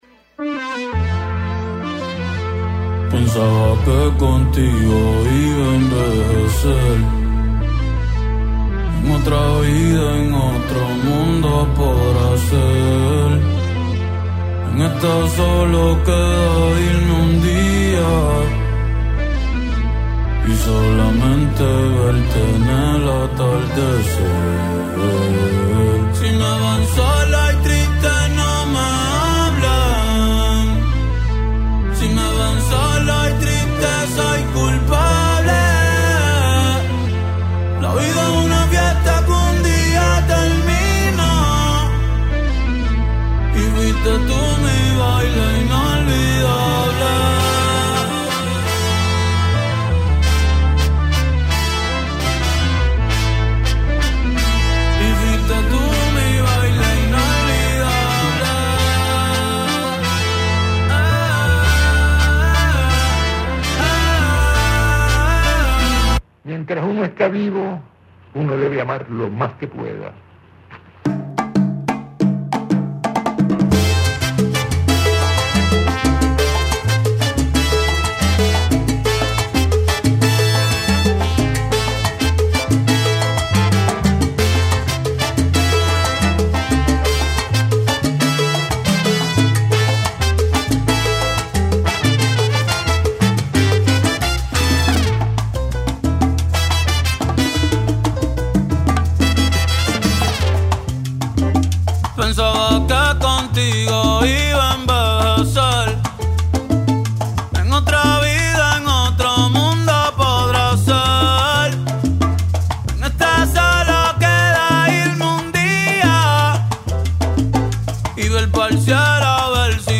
Broadcast Friday afternoons from 4 to 6pm on WTBR.